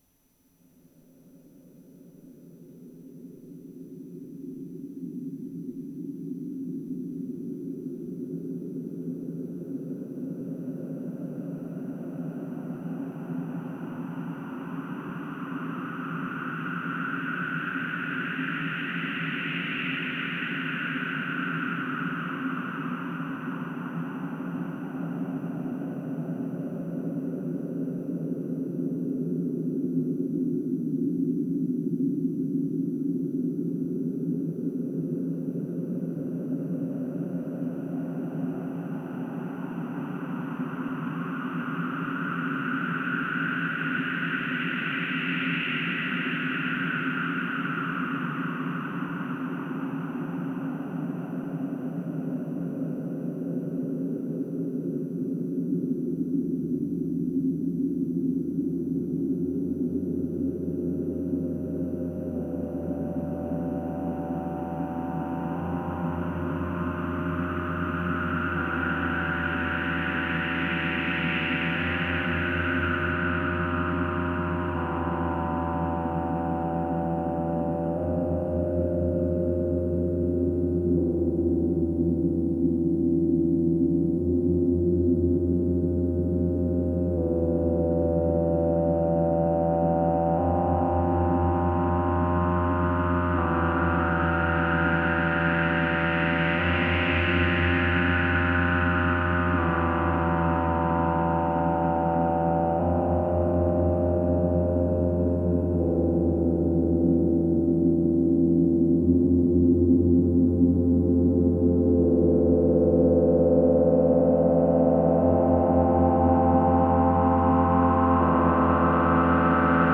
Ce titre est classé dans les compositions car il est basé sur les patches des jours précédents et une ambiance qui m'a inspiré toute l'œuvre.
En fait, la répétition des deux notes que l'on entend tout au long du titre m'a rappelé une chanson de Gérard Manset que j'écoutais quand j'étais jeunes.
• Format : ogg (stéréo)